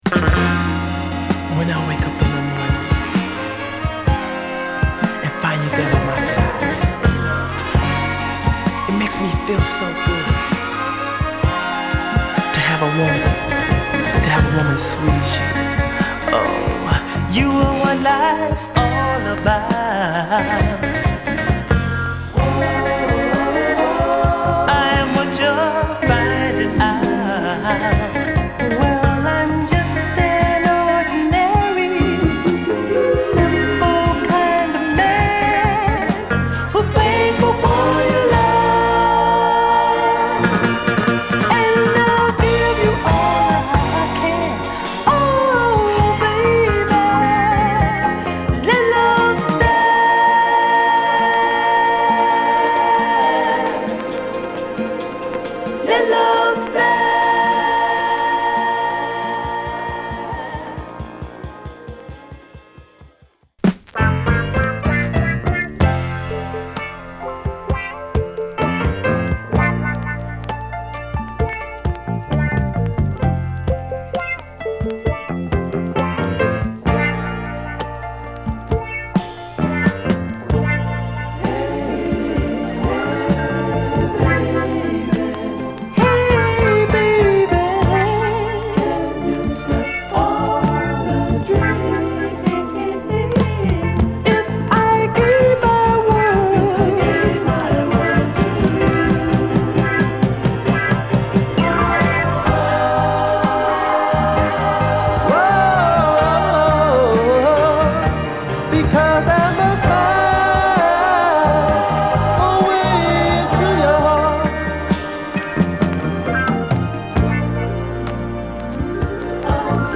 印象的なストリングス・アレンジが映える、男気哀愁系のグッド・メロウ・ソウルのA
柔らかなピアノ&ストリングスが夢見心地にさせてくれるB